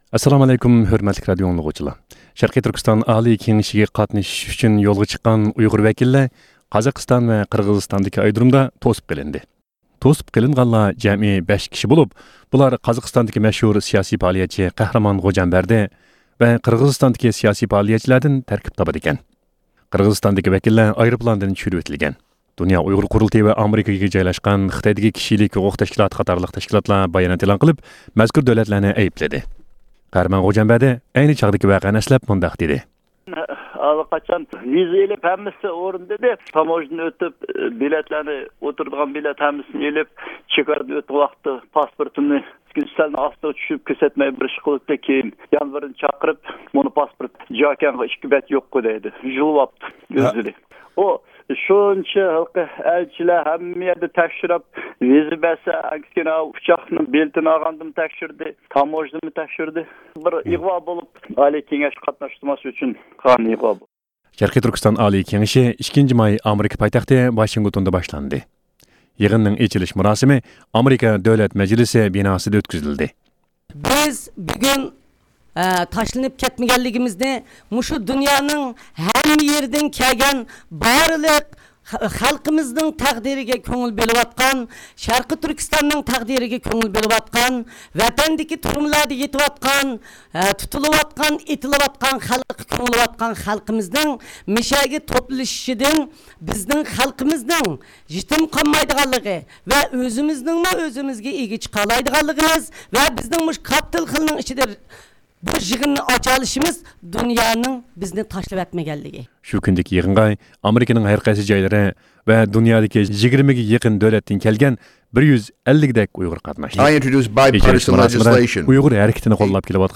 ھەپتىلىك خەۋەرلەر (30-ئاپرېلدىن 6-مايغىچە) – ئۇيغۇر مىللى ھەركىتى